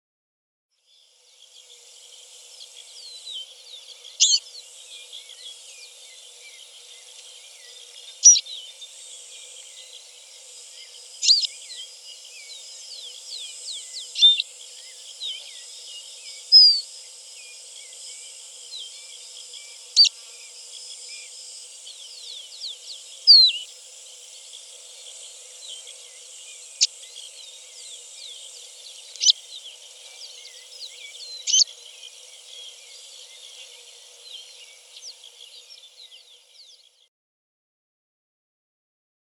Distinguishing females of capuchino seedeaters: calls repertoires provide evidence for species-level diagnosis PDF Supplemental material